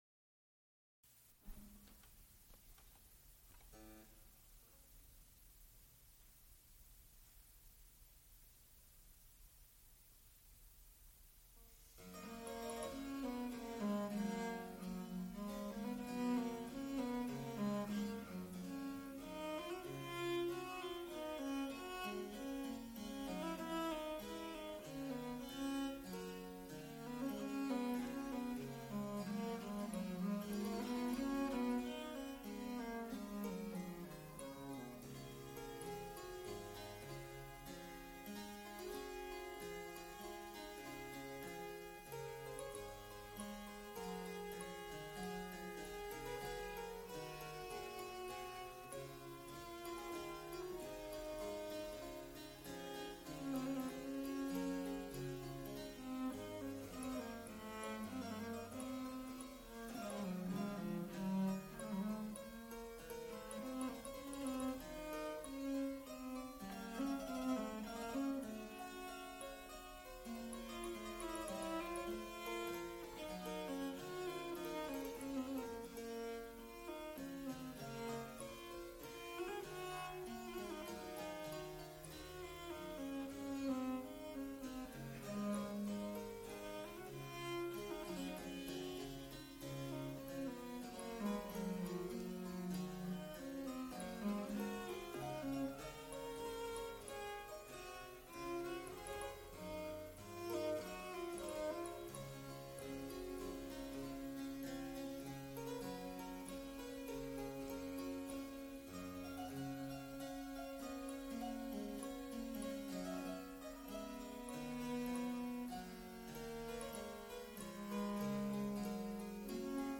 oboe, recorder
Recorded live February 16, 1978, Frick Fine Arts Auditorium, University of Pittsburgh.
Extent 3 audiotape reels : analog, quarter track, 7 1/2 ips ; 7 in.
Sacred songs (High voice) with continuo